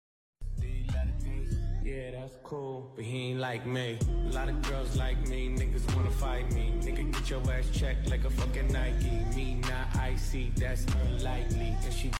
ignore how the sound is a little bit off